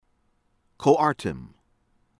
CLWYD, ANN ANN   KLOO-wihd